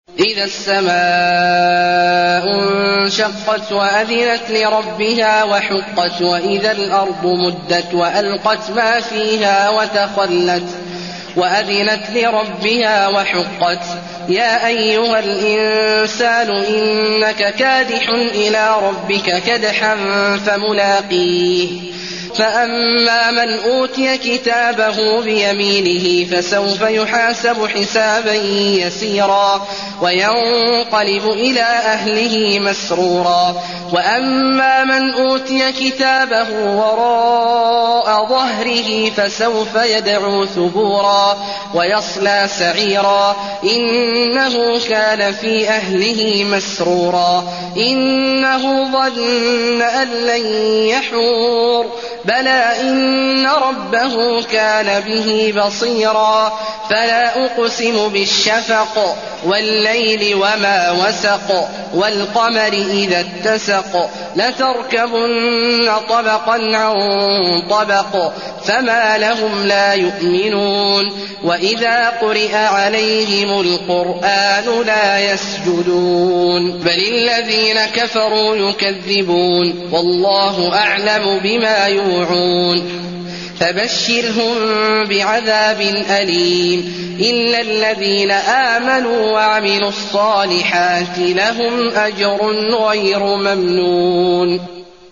المكان: المسجد النبوي الانشقاق